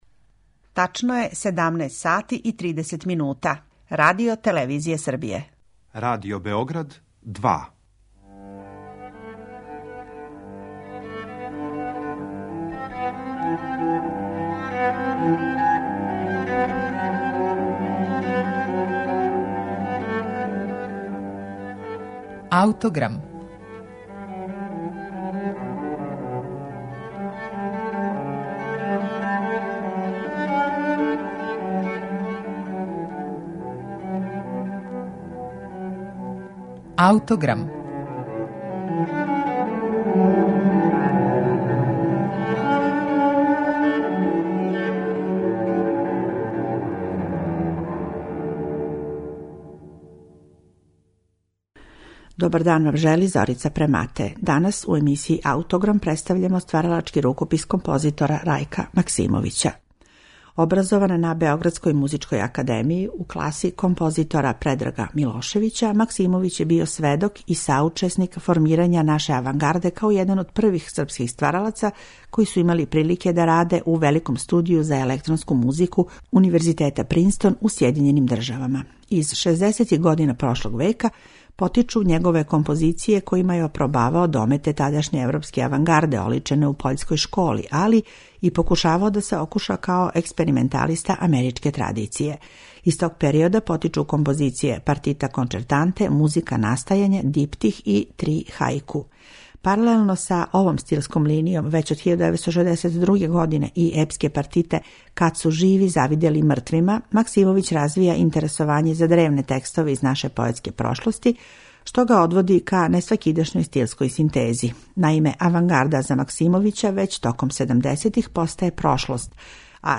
На нашем снимку пева сопран